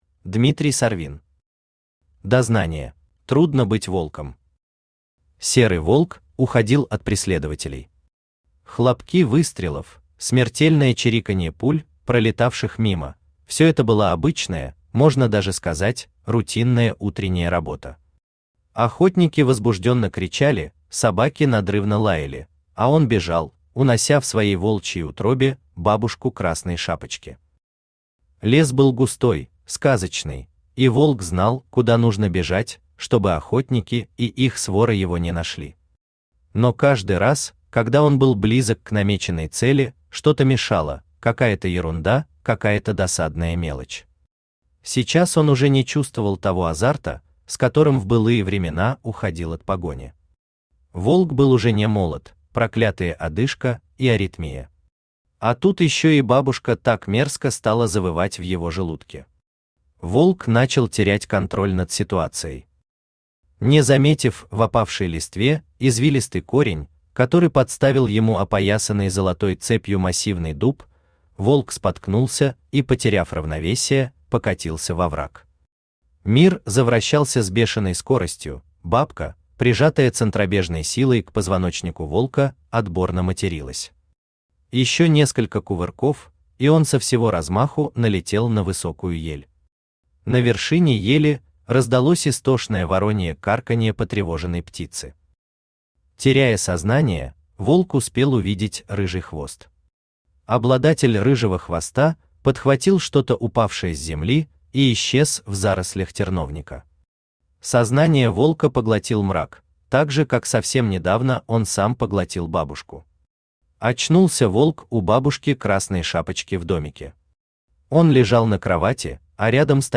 Аудиокнига Дознание | Библиотека аудиокниг
Aудиокнига Дознание Автор Дмитрий Васильевич Сарвин Читает аудиокнигу Авточтец ЛитРес.